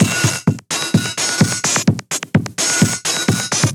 VEH1 Fx Loops 128 BPM
VEH1 FX Loop - 30.wav